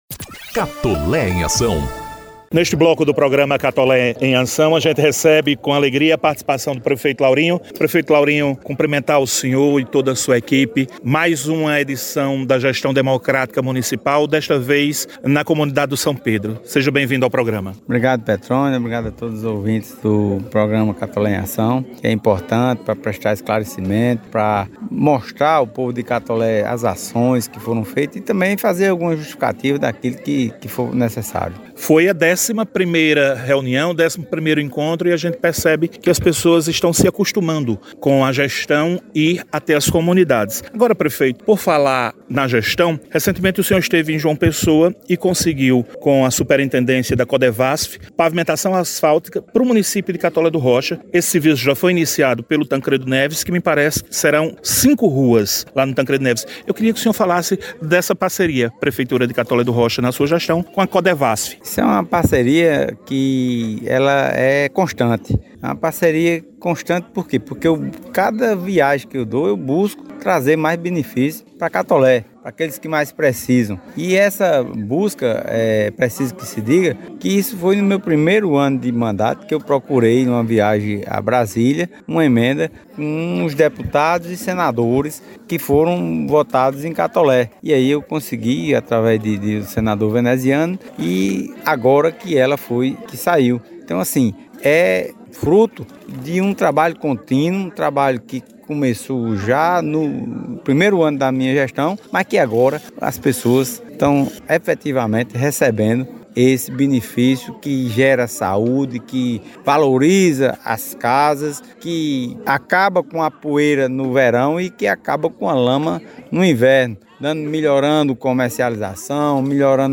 Ouça na íntegra a entrevista do Prefeito Laurinho Maia no programa Catolé em Ação desta sexta-feira (29) onde falou sobre os serviços e obras de infraestrutura, saúde e esporte - Folha Paraibana